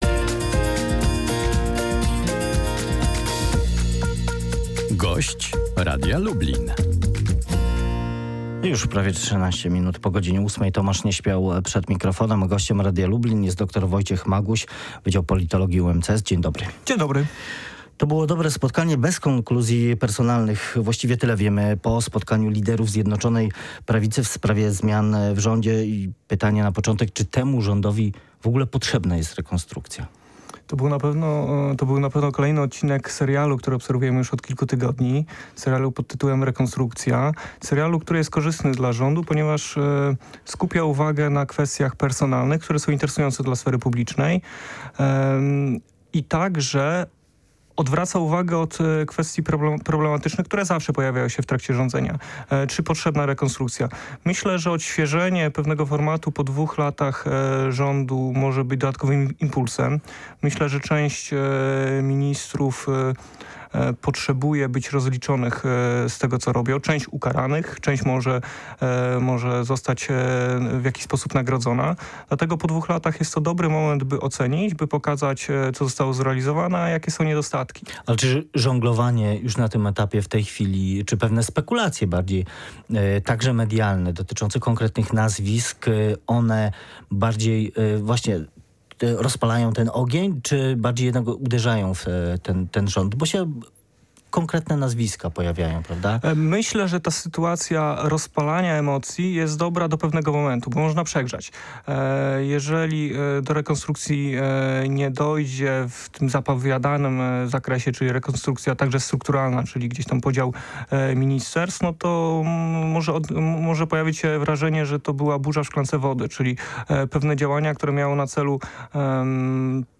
Politolog o rekonstrukcji rządu: Rewolucji nie będzie